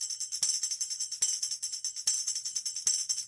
描述：一个手鼓的节奏模式
Tag: 手鼓 叮当 节奏 坦博 图案 命中 争吵